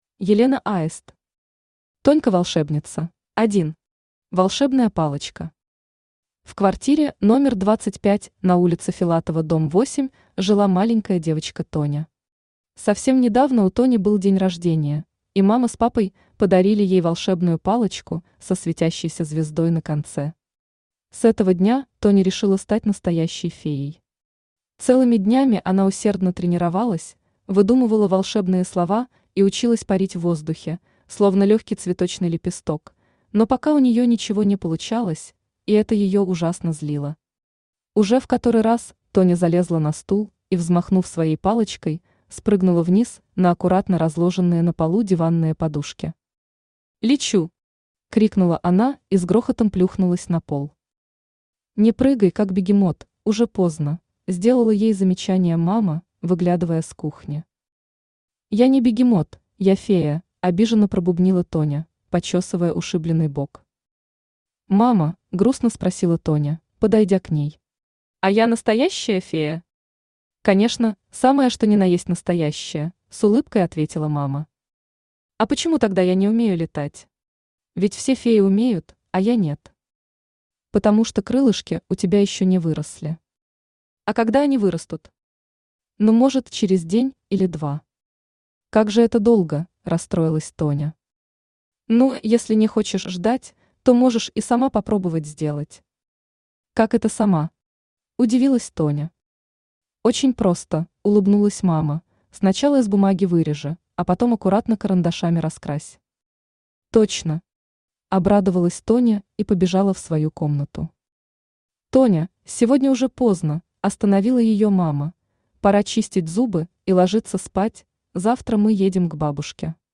Аудиокнига Тонька волшебница | Библиотека аудиокниг
Aудиокнига Тонька волшебница Автор Елена Аист Читает аудиокнигу Авточтец ЛитРес.